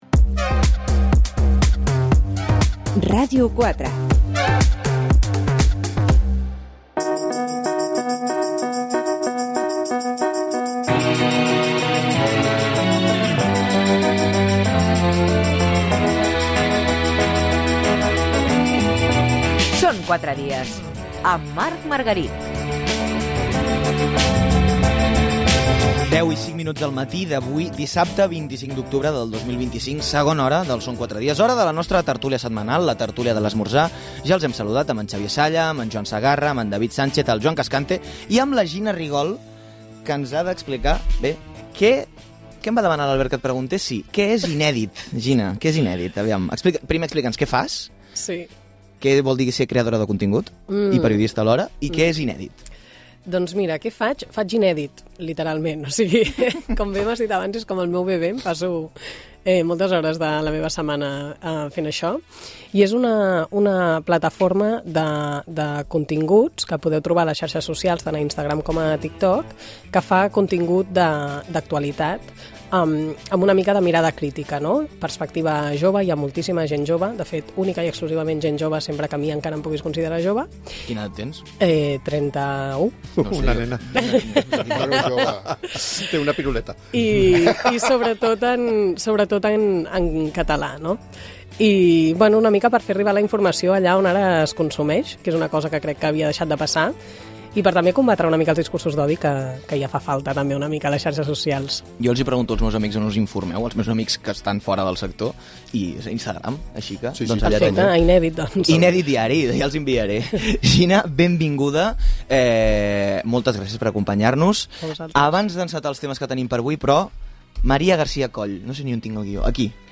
Tertúlia al Són 4 dies de Ràdio 4